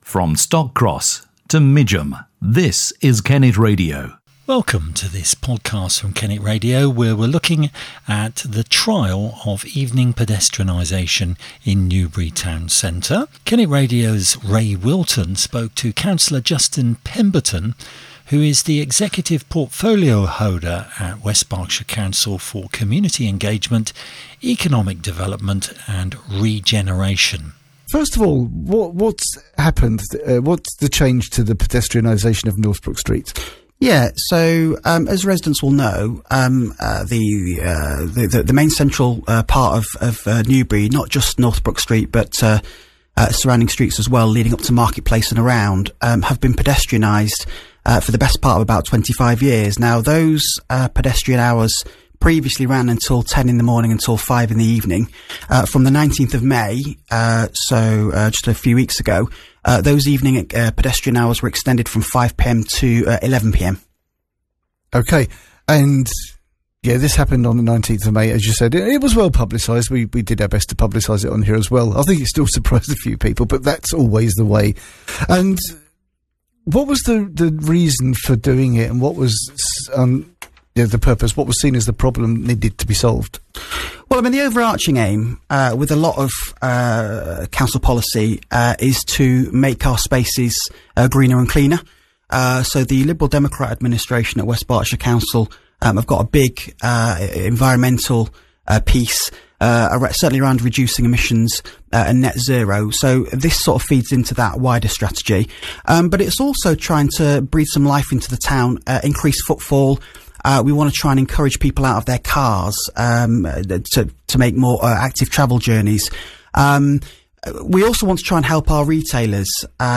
In this podcast, Councillor Justin Pemberton, the council’s executive portfolio holder for Community Engagement, Economic Development and Regeneration, answers questions about the trial.